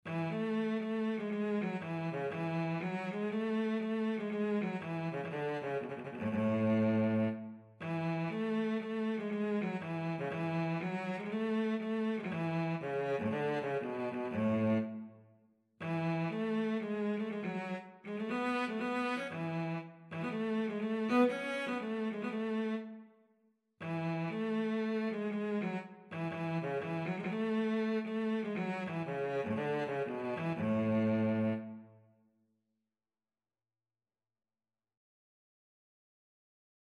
Traditional Music of unknown author.
4/4 (View more 4/4 Music)
A major (Sounding Pitch) (View more A major Music for Cello )
Cello  (View more Easy Cello Music)
Traditional (View more Traditional Cello Music)